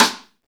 Index of /90_sSampleCDs/Club-50 - Foundations Roland/SNR_xCrossSticks/SNR_xCrossSticks